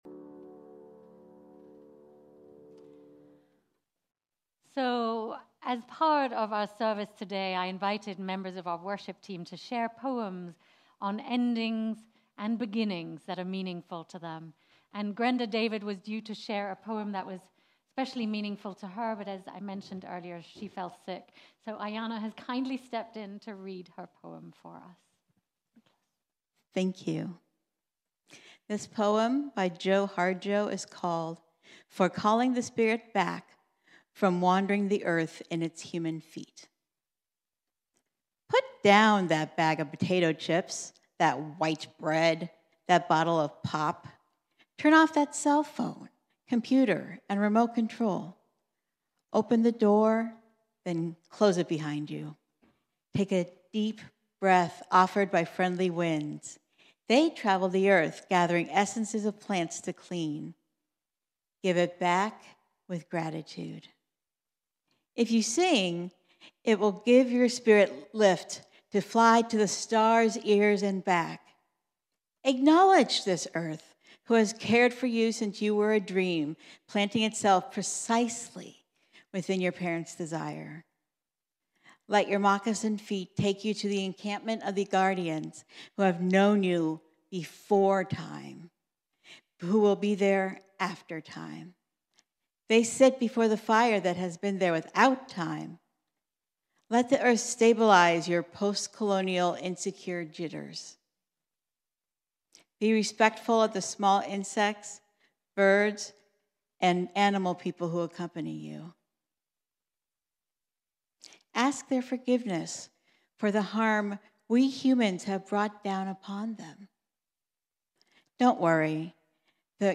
After 16 years, today marks our last service in our current Sanctuary. We take time to bid adieu while we welcome in our new chapter in Fellowhip Hall.